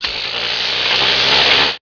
spin.wav